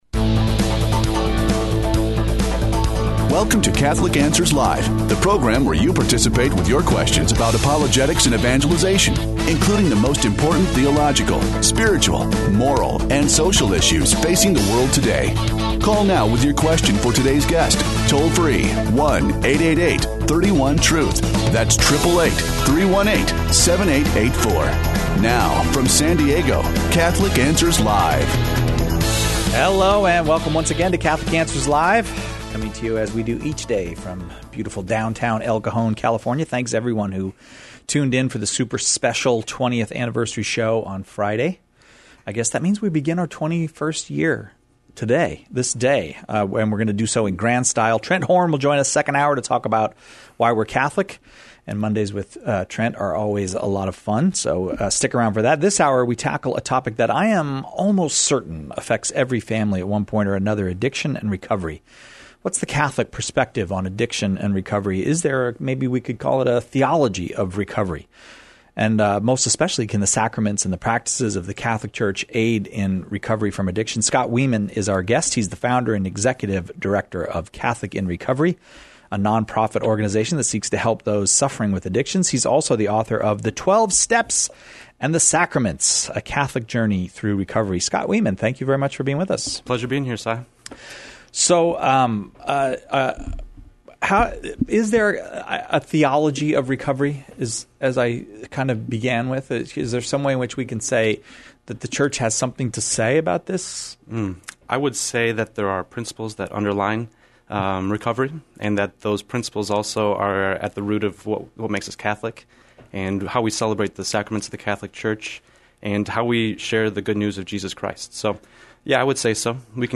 answers caller questions about overcoming addiction